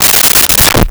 Umbrella Open 02
Umbrella Open 02.wav